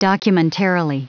Prononciation du mot documentarily en anglais (fichier audio)
Prononciation du mot : documentarily